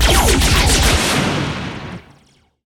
laser.ogg